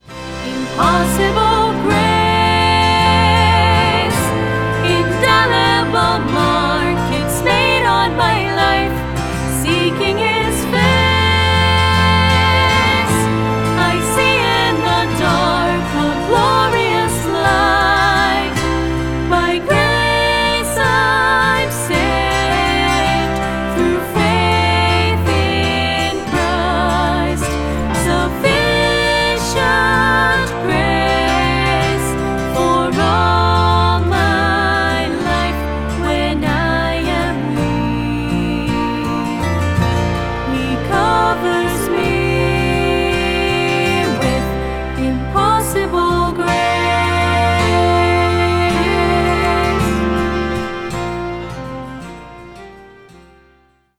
The songs feature vocals with piano and orchestration.